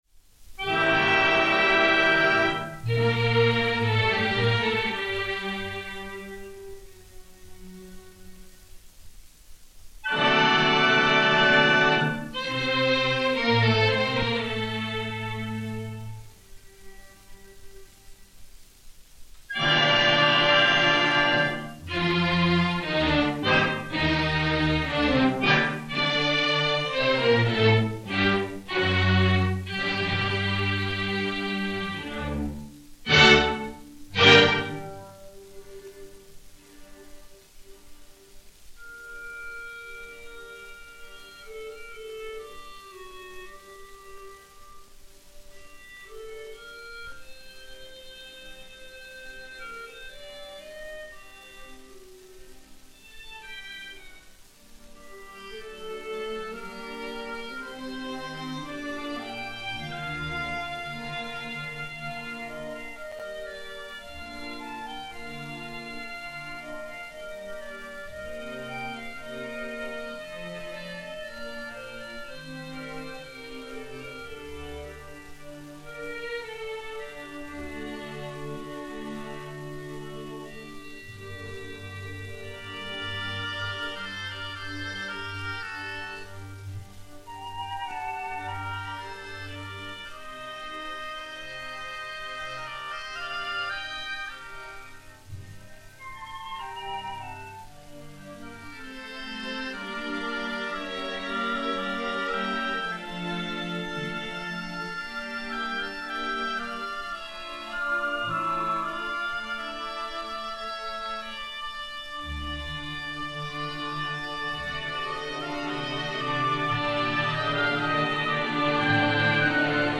vibrante, nerveuse et colorée
Ouverture